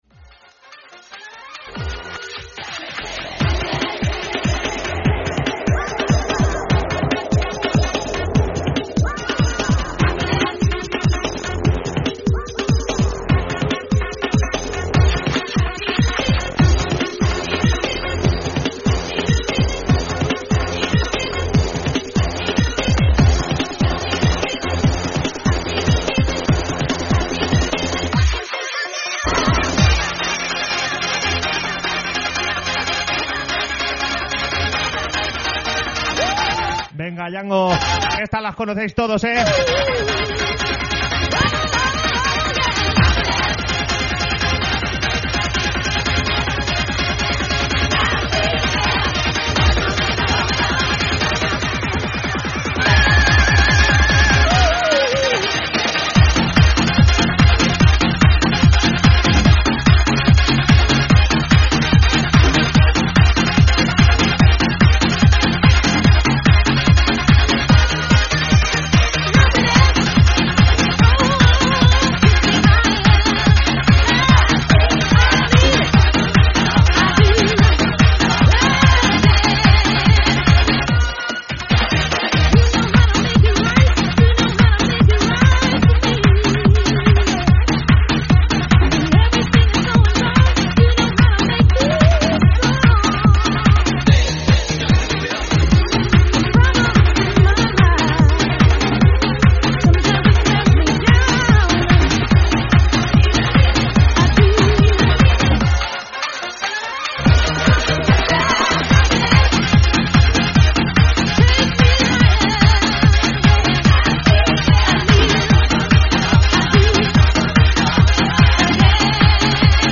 Sesiones de deejays Sumérgete en la mejor selección musical con nuestras sesiones exclusivas de DJs. Ritmos que conectan, mezclas únicas y la energía del directo para que no dejes de moverte.